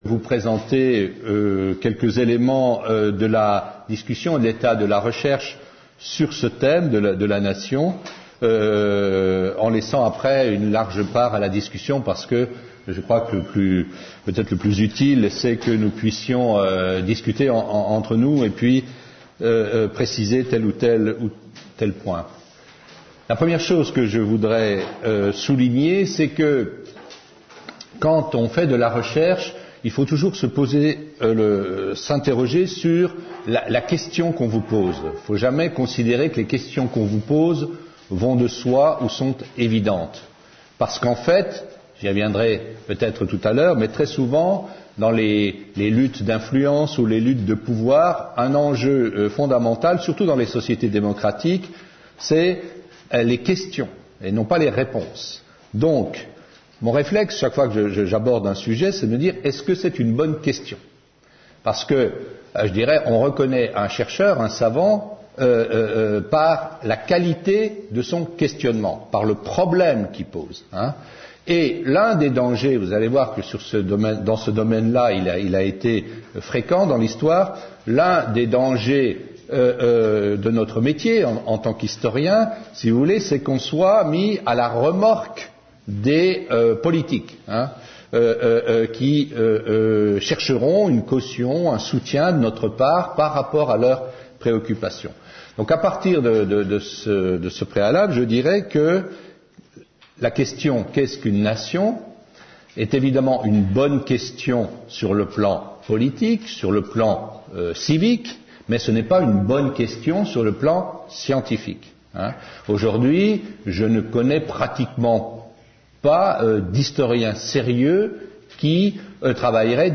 Une conférence de l'UTLS au lycée avec Gérard Noiriel (philosophe) Lycée Maximilien Perret (Alforville 94)